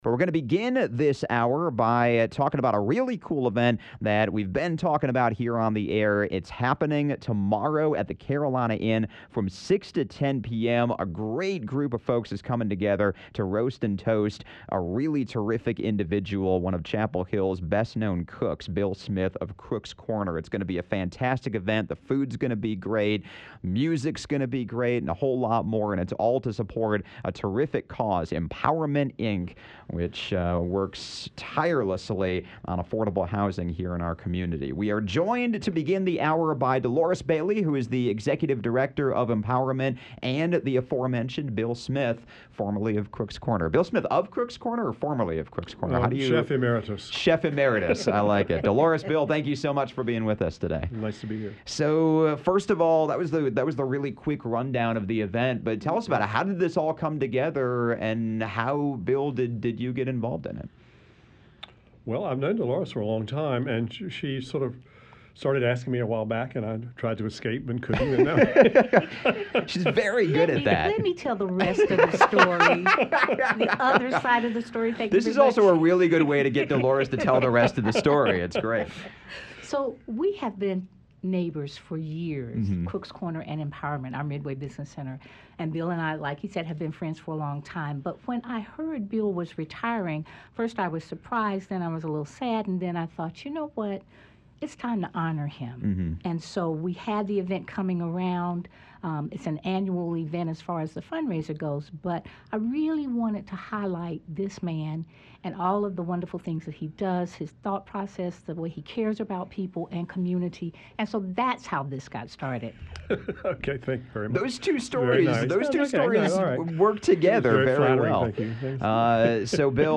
Conversation sponsored by Empowerment, Inc.